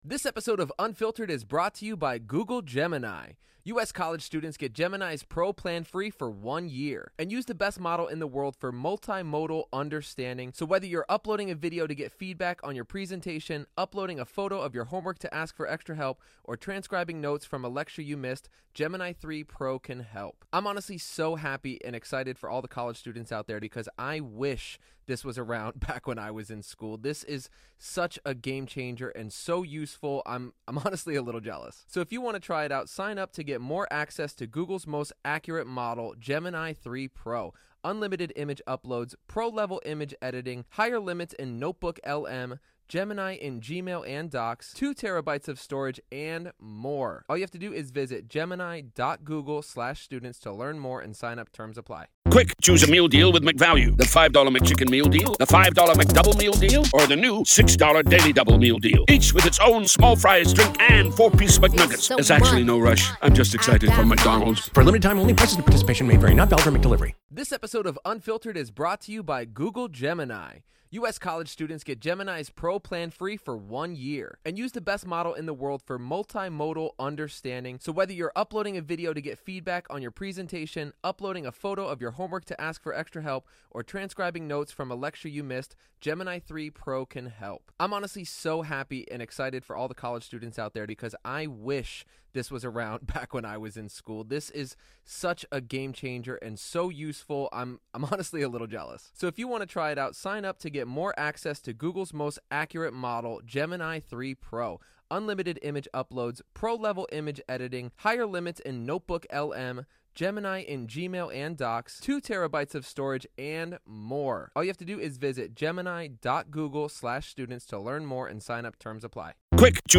LIVE COURTROOM COVERAGE — NO COMMENTARY